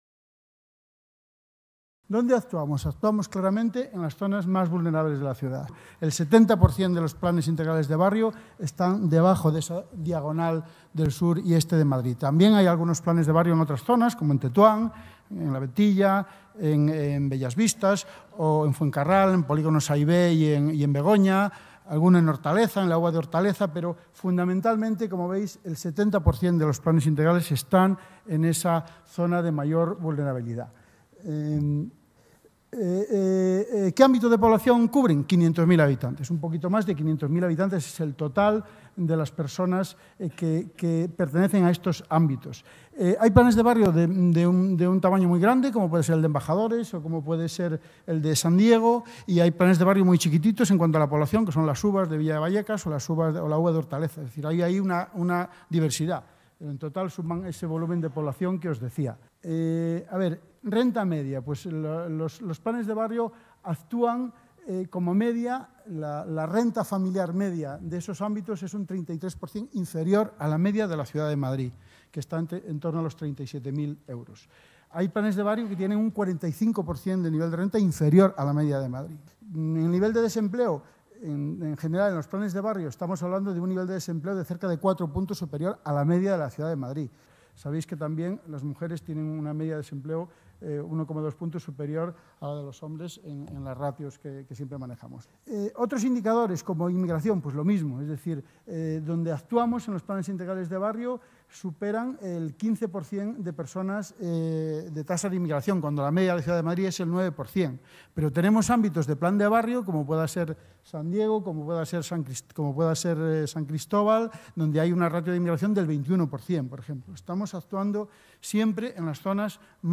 José Antonio Díaz Méndez, Director General de Descentralización, dice que los Planes Integrales de Barrio actúan en las zonas más vulnerables de la ciudad